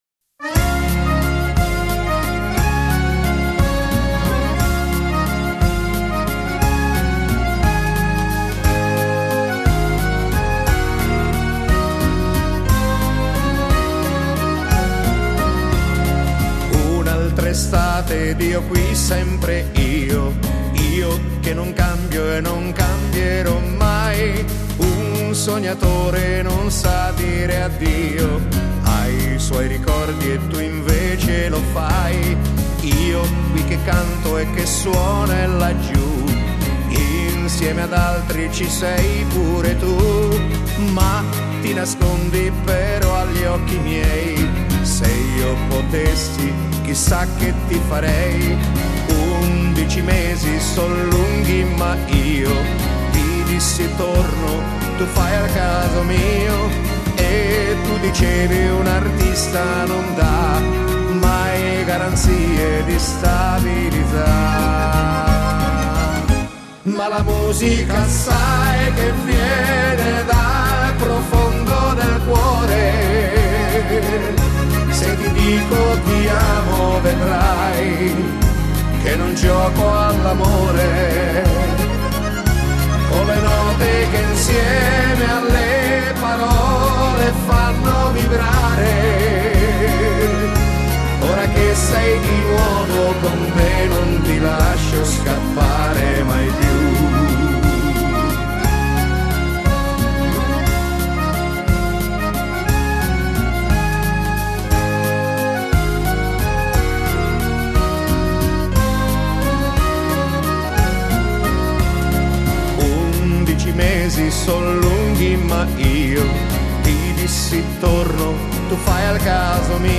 Genere: Valzer